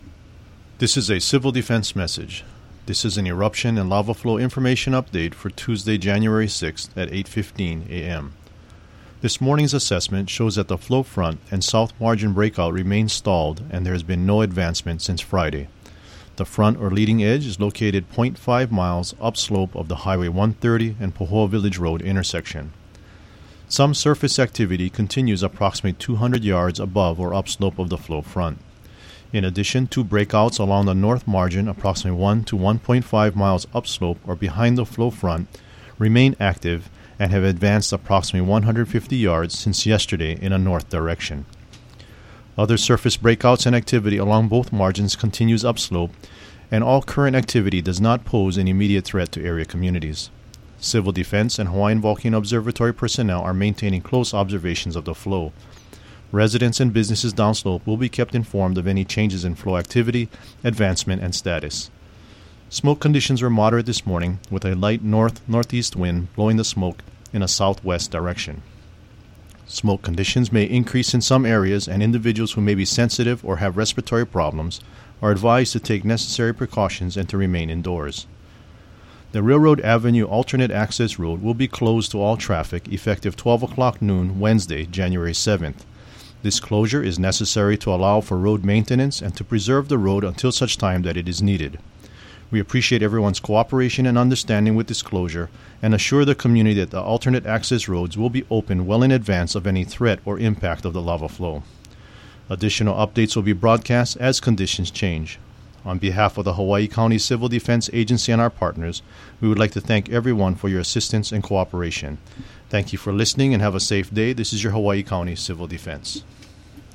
This morning’s Civil Defense message: